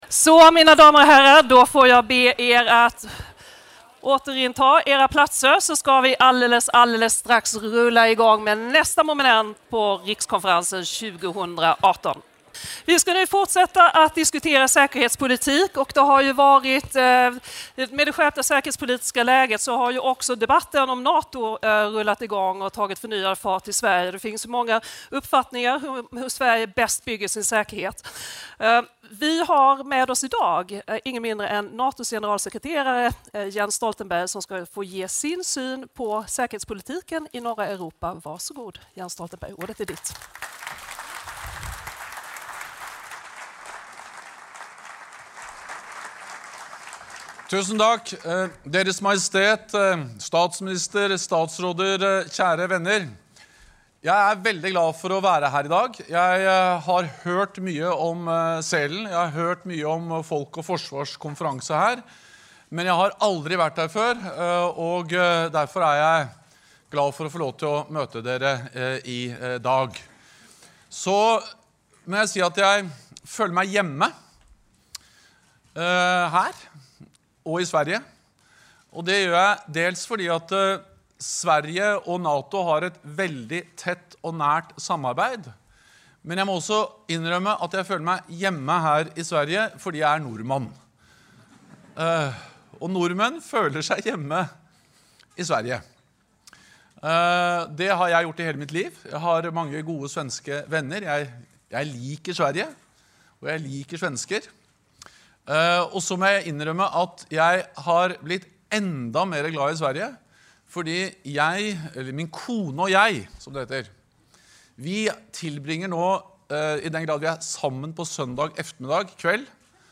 NATO Secretary General Jens Stoltenberg welcomed Sweden’s important contributions to the Alliance at the Folk och Försvar Security Conference in Sälen on Sunday (14 January 2018). In a keynote speech, the Secretary General outlined NATO’s adaptation to a challenging security environment. He stressed the importance of the close cooperation with Nordic partners like Sweden helps to strengthen security in the region.